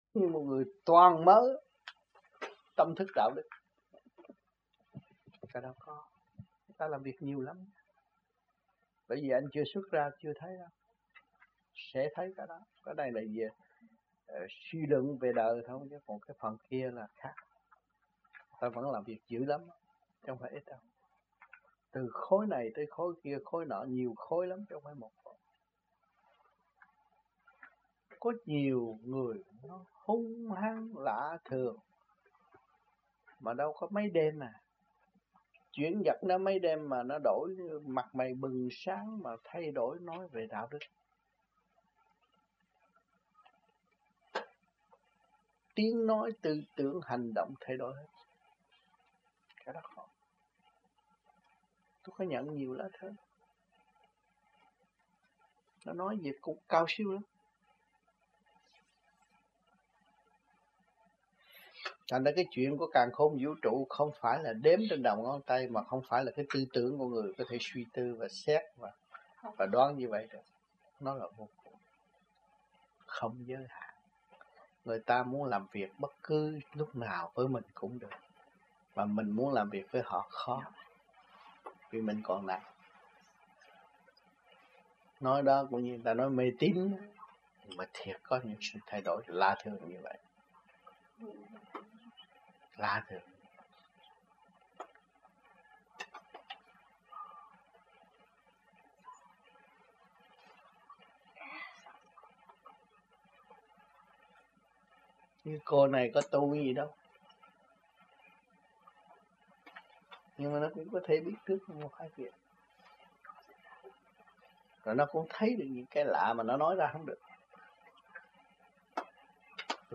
Luận Đàm